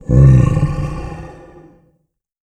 MONSTER_Growl_Medium_11_mono.wav